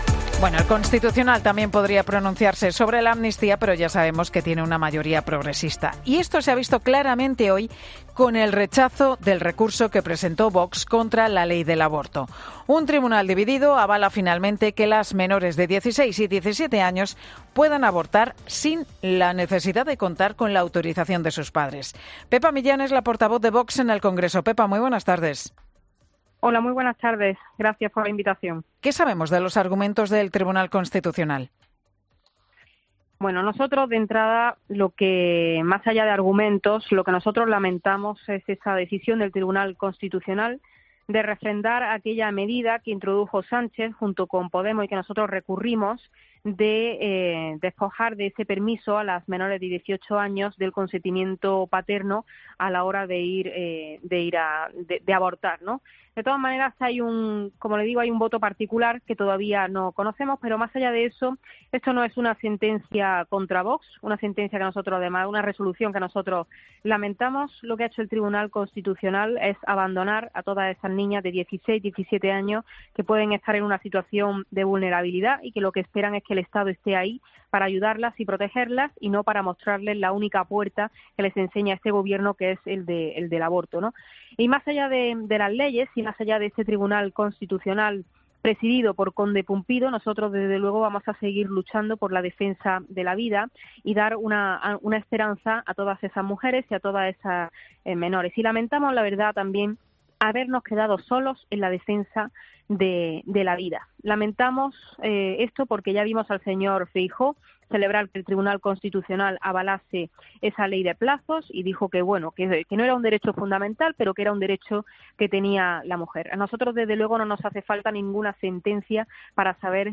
Pepa Millán, portavoz de Vox en el Congreso, en Mediodía COPE sobre su recurso contra el aborto